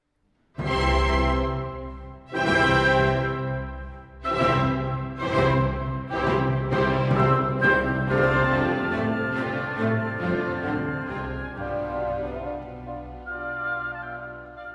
Orchestre : 1 flûte, 2 hautbois, 2 bassons, 2 cors en Ut, 2 trompettes en Ut, 2 timbales (Ut et Sol), quatuor.
I. ALLEGRO MAESTOSO
1. Tutti d'introduction
Un frontispice grandiose et héroïque. De brillants accords sur l'arpège du ton dans la nuance f par le tutti auquel les bassons et les hautbois apportent une douce réponse dans la nuance p. Reprise de cette entrée, mais cette fois, la réplique des bois se fait en mineur (env. 0'30'').